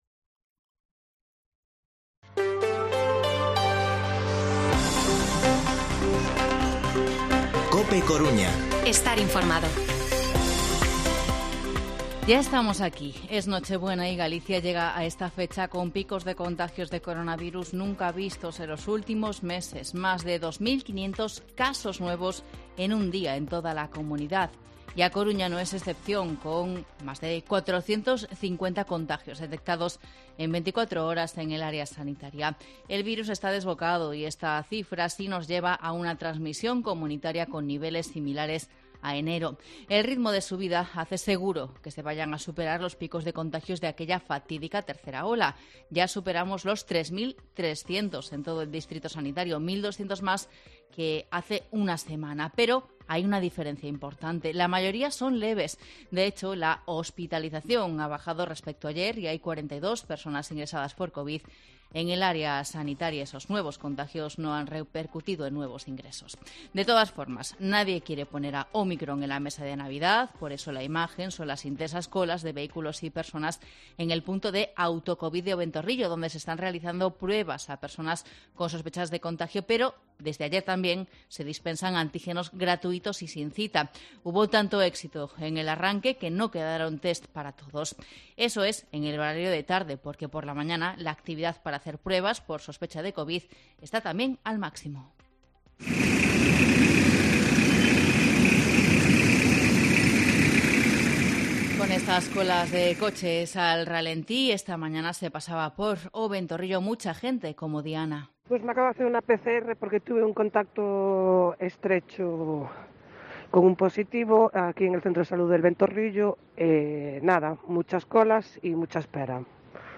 Informativo mediodía COPE Coruña viernes, 24 de diciembre de 2021